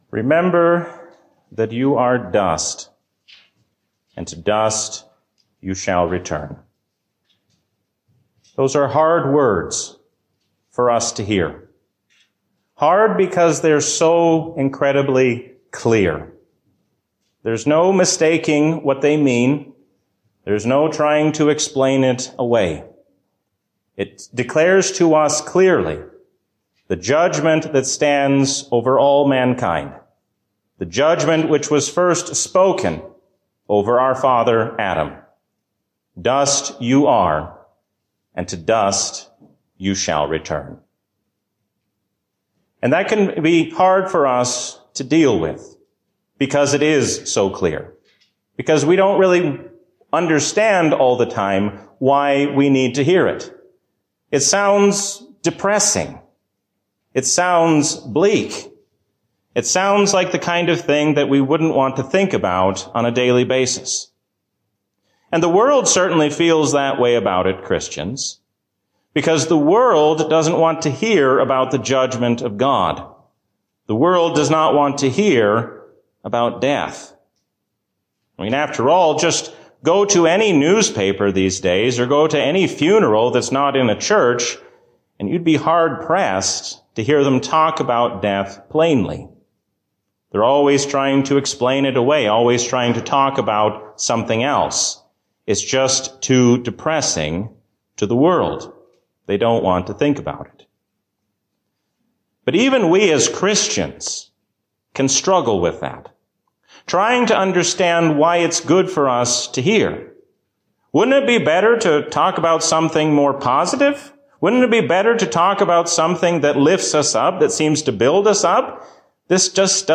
A sermon from the season "Lent 2021." God gives us hope in His Son even when the future seems uncertain.